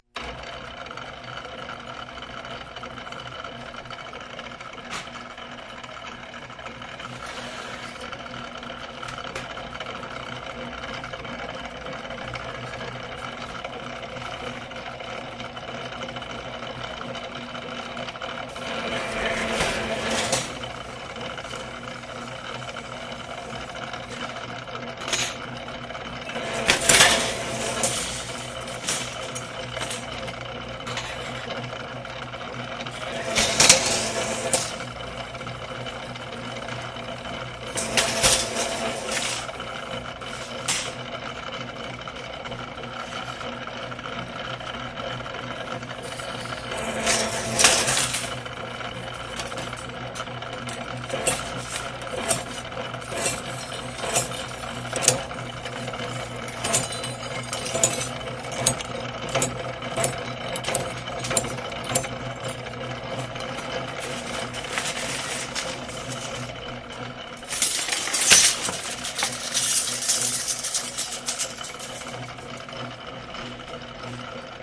金属店" 机器 金属切割机 磨床 辊子 送料 弯曲件2
描述：机器金属切割机研磨机辊子喂料弯曲件.flac
Tag: 切割机 弯曲 金属 磨床 饲料